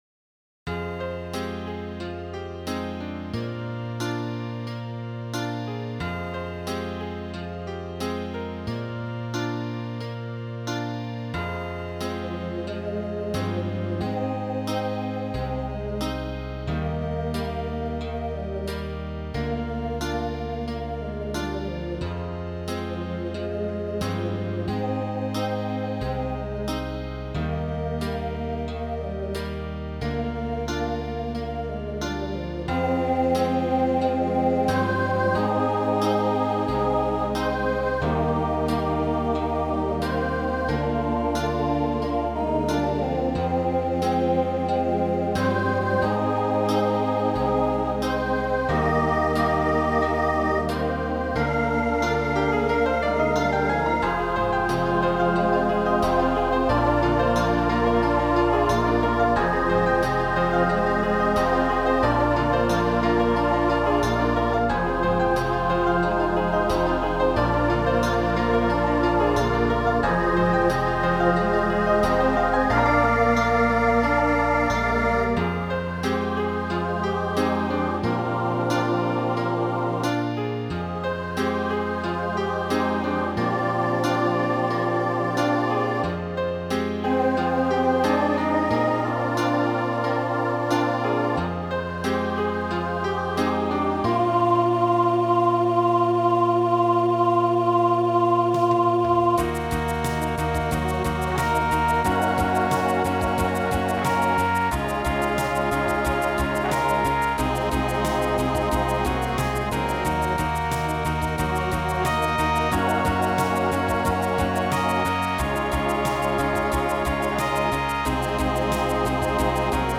Voicing SATB Instrumental combo Genre Pop/Dance
Show Function Ballad